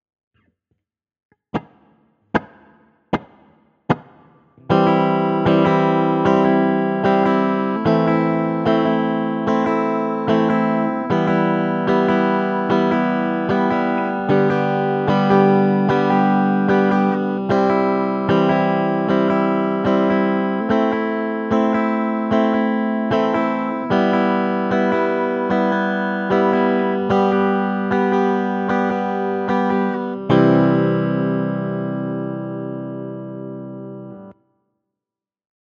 Rythme: double croche -croche pointée
Audio : 4 X BH(B)(H) sur chaque accord (DO lAm MIm SOL)